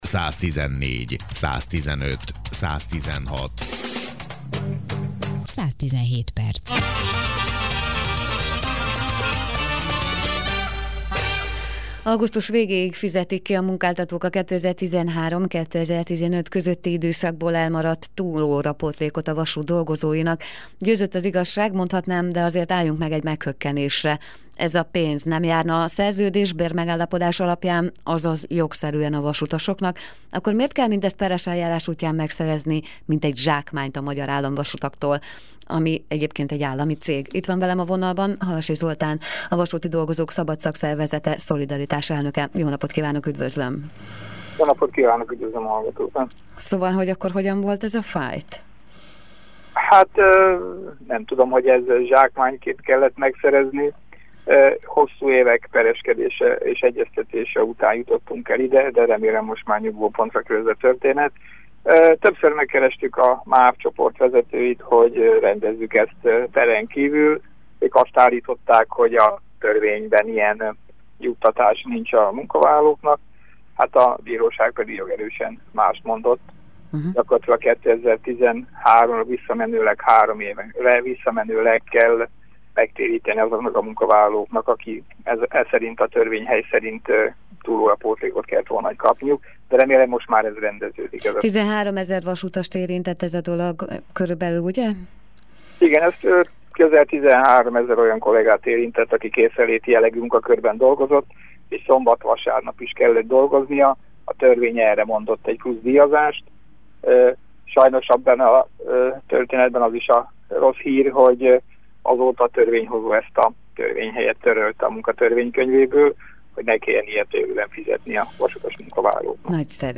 Interjú.